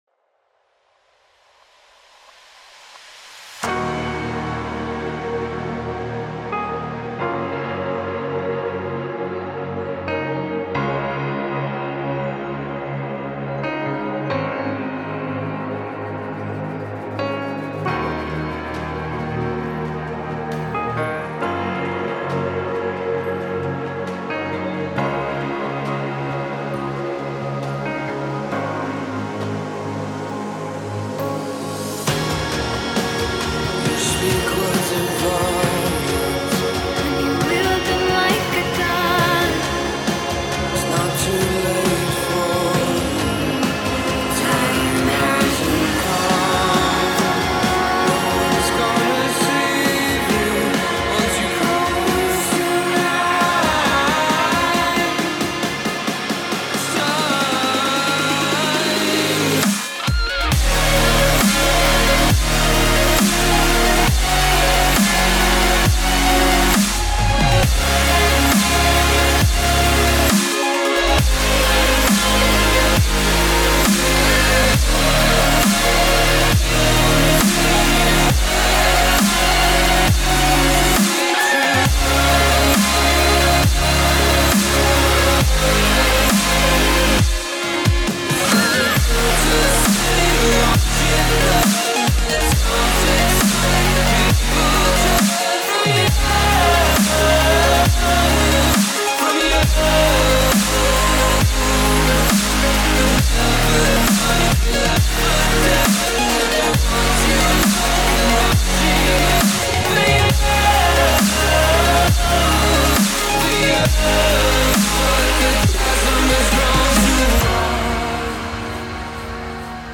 это атмосферная электронная композиция в жанре синти-поп